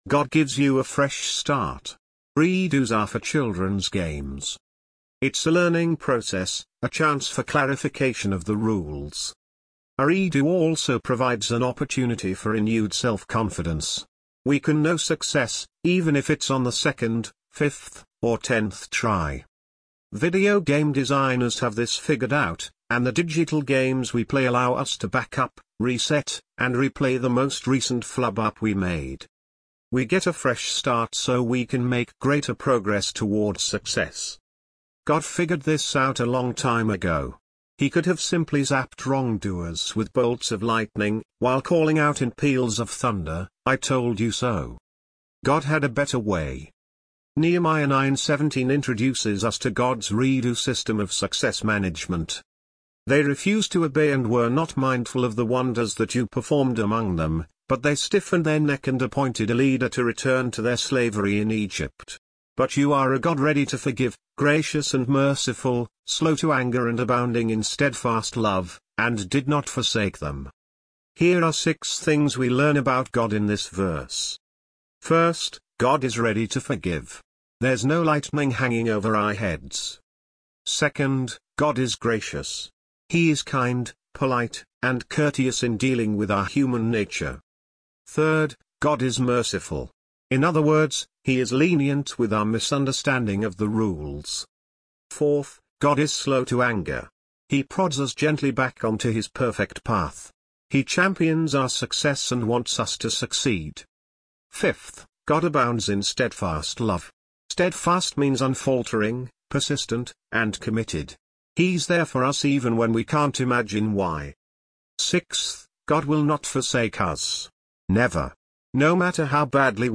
English Audio Version